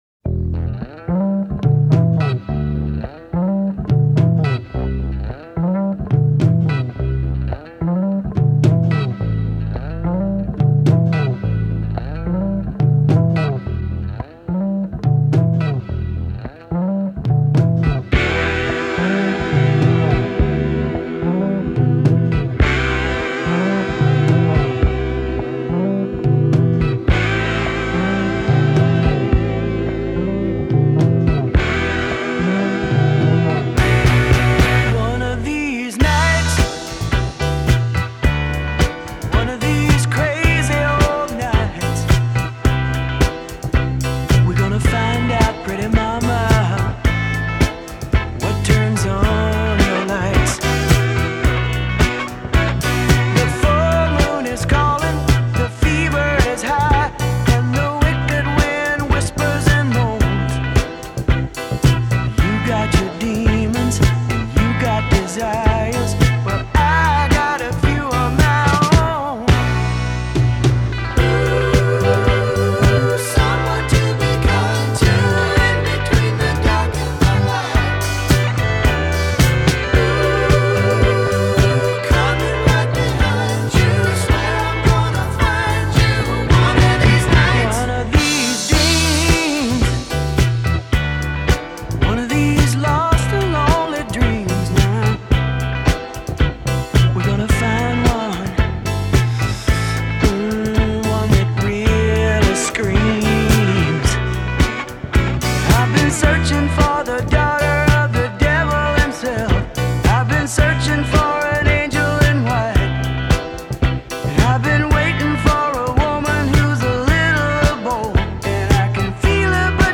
Rock, Country Rock